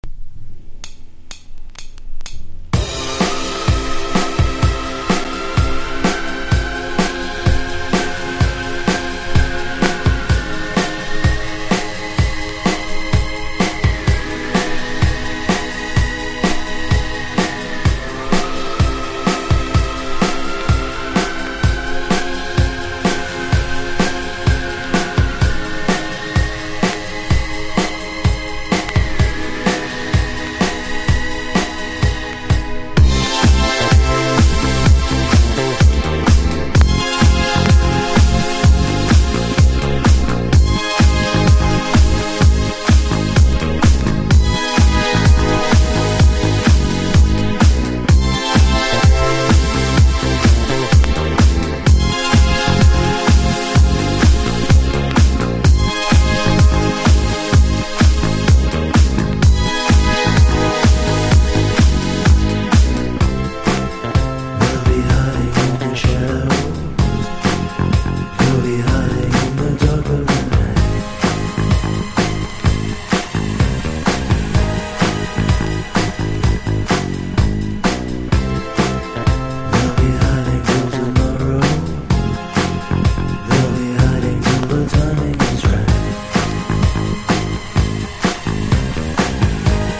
ELECTRO
軽やかなシンセが飛び交うディスコポップ･チューン。